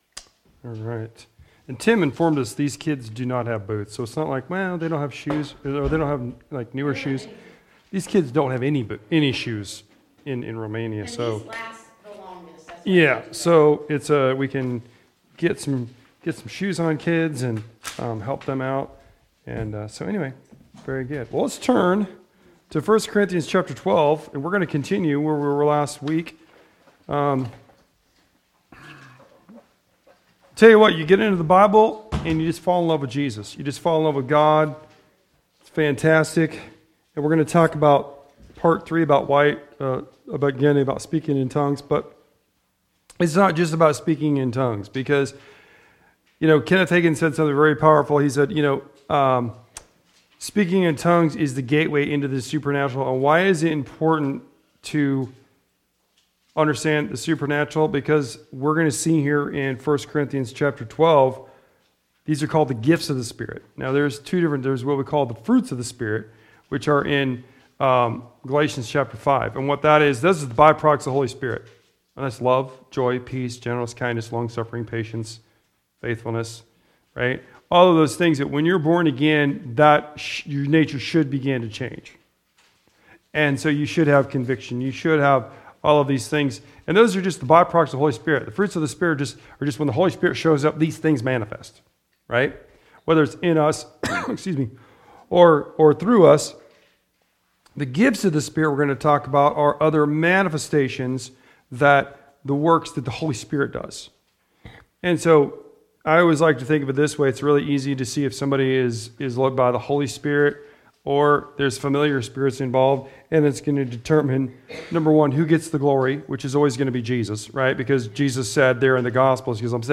Weekly messages/sermons